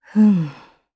Isolet-Vox_Think_jp.wav